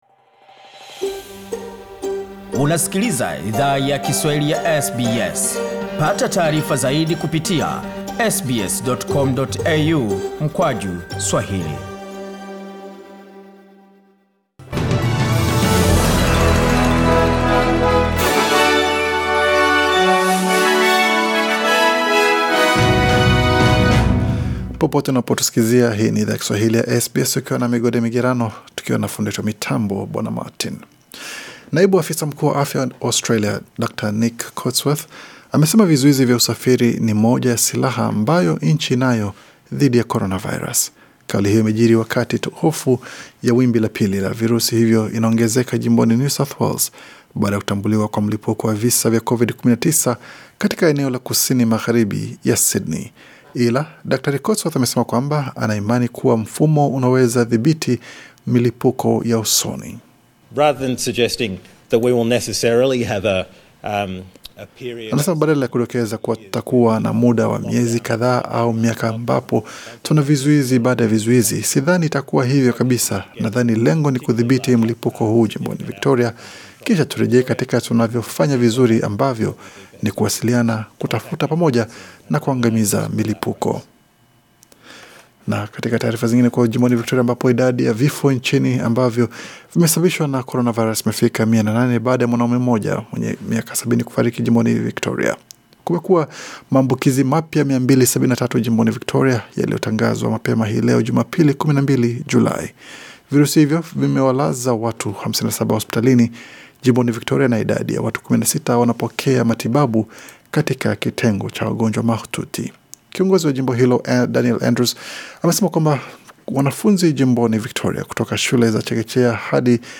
Taarifa ya habari 12 Julai 2020